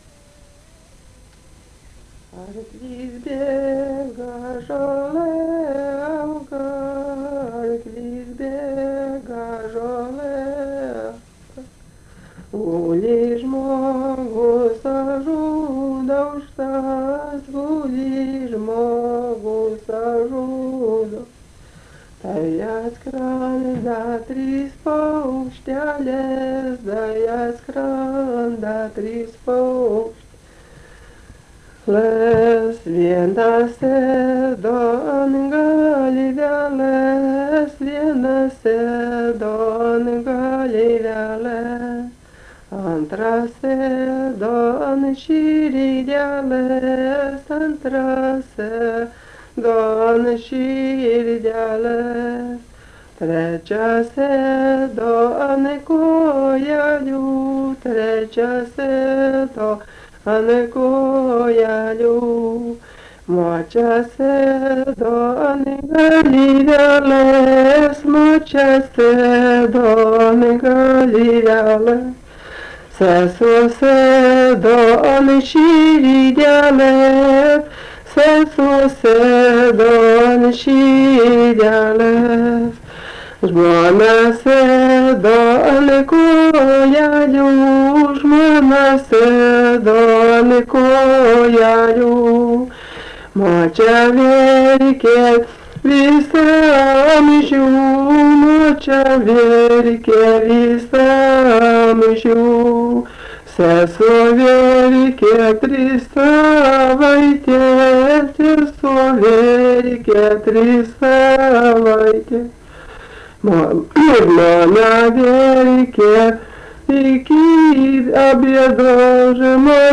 LMTA Muzikinio folkloro archyvas · omeka